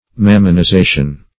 Search Result for " mammonization" : The Collaborative International Dictionary of English v.0.48: Mammonization \Mam`mon*i*za"tion\, n. The process of making mammonish; the state of being under the influence of mammonism.